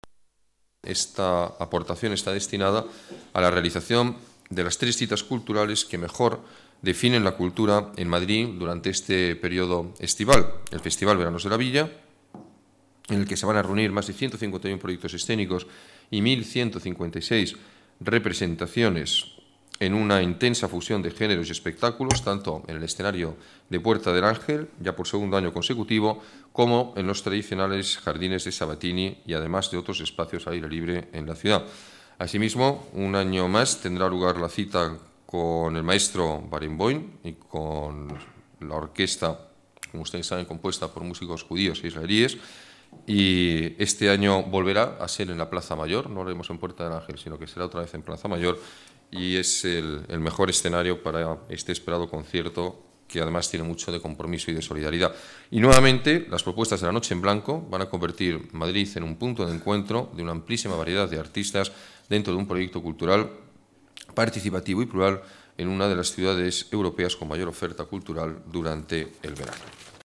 Nueva ventana:Declaraciones alcalde, Alberto Ruiz-Gallardón: más dinero para la oferta cultural veraniega